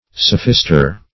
sophister - definition of sophister - synonyms, pronunciation, spelling from Free Dictionary
Sophister \Soph"ist*er\, n.